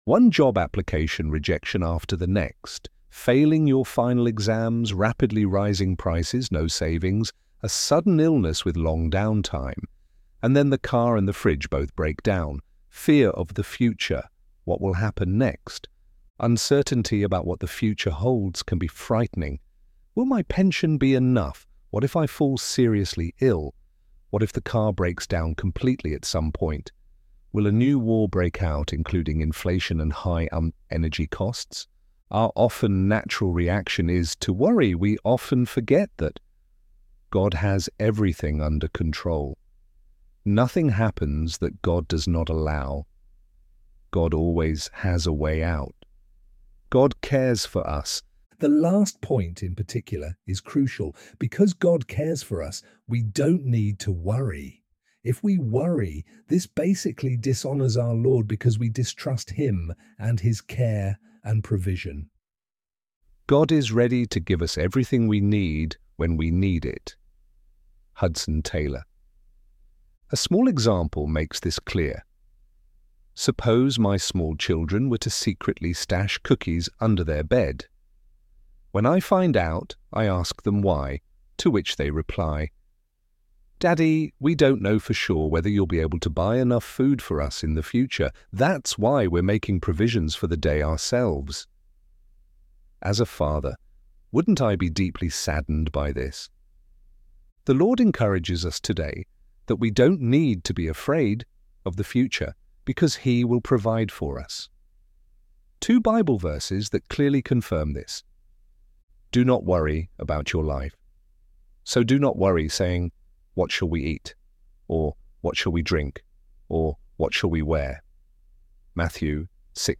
ElevenLabs_care_provision.mp3